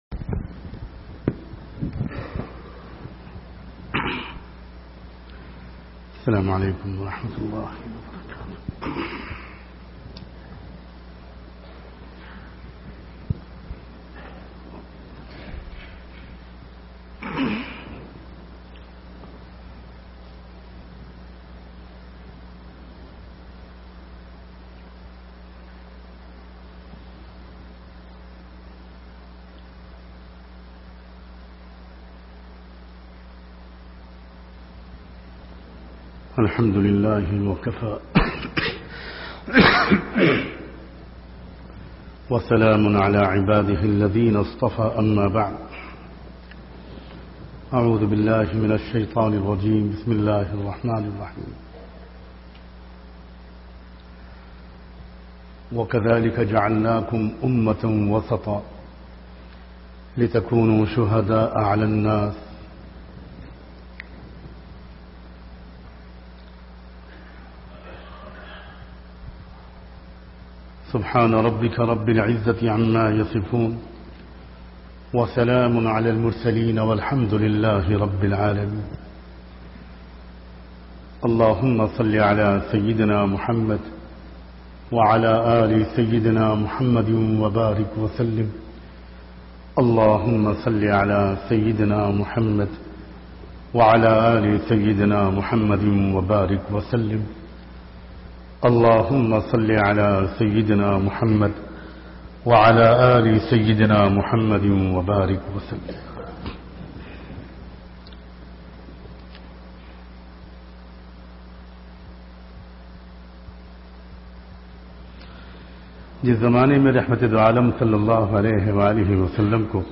Bayanat - Social Cares